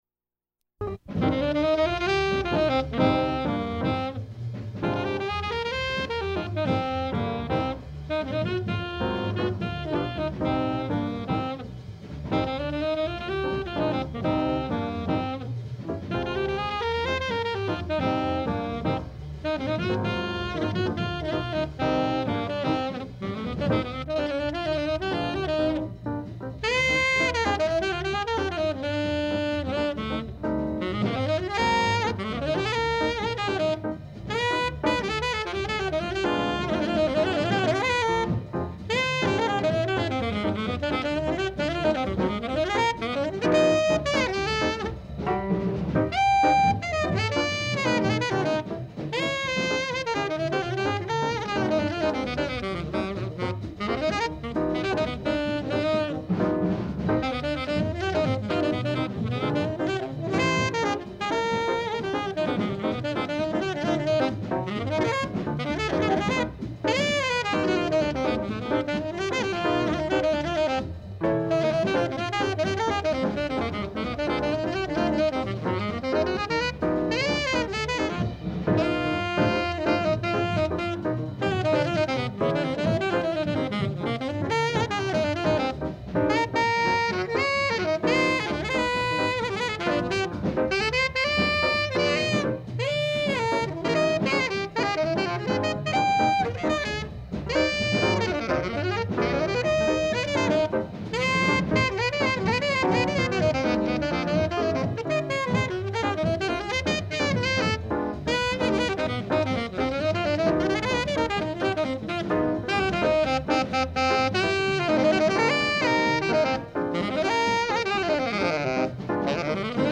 Bed of background music